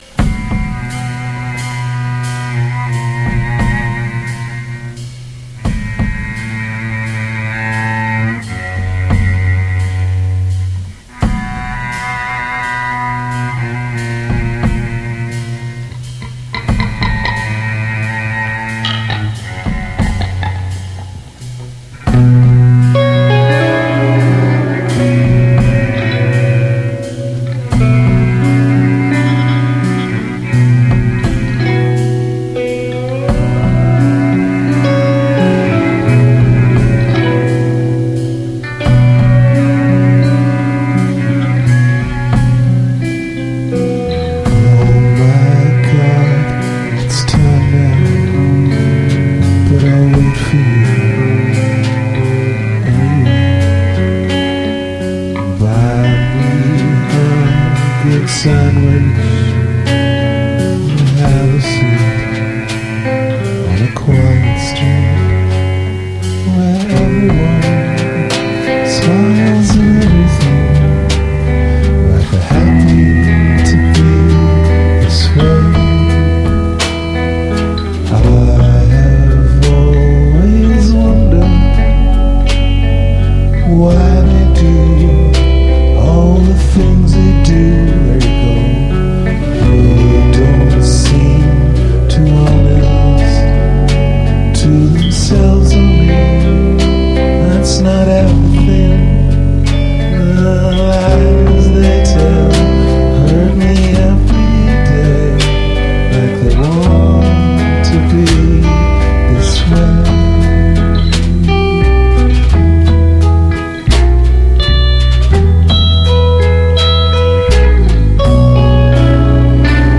sandwiches try w a r'n'r ending
bunker beaters folk
Guitar rig 5 actually.